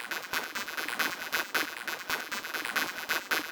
122 bpm Free sound effects and audio clips
• techno melodic synth sequence.wav
techno_melodic_synth_sequence_0x6.wav